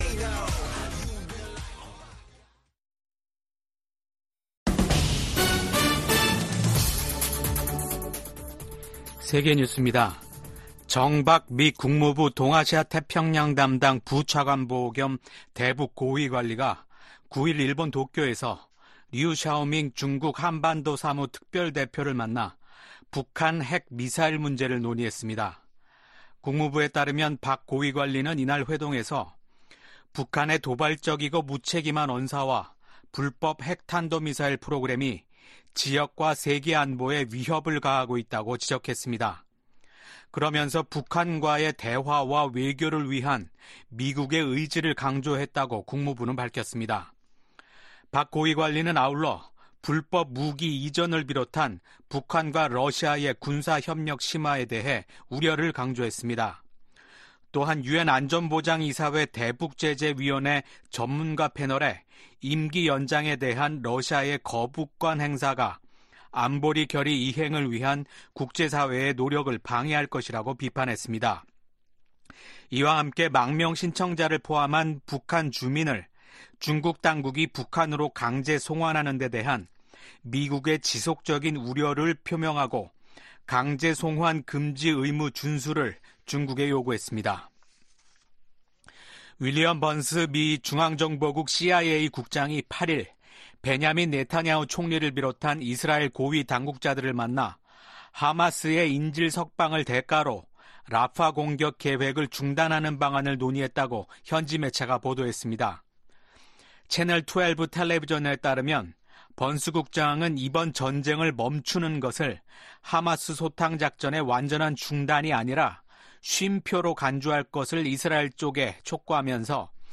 VOA 한국어 아침 뉴스 프로그램 '워싱턴 뉴스 광장' 2024년 5월 10일 방송입니다. 윤석열 한국 대통령은 취임 2주년 기자회견을 열고 오는 11월 미국 대통령 선거 결과와 관계없이 미한 양국간 동맹관계는 변치 않을 것이라고 말했습니다. 지난 두 달여 동안 북한 남포 유류 항구에 최소 18척의 유조선이 입항한 것으로 나타났습니다. 미 국무부는 북한이 사이버 범죄와 암호화폐 탈취 등 악의적인 사이버 활동을 확대하고 있다고 지적했습니다.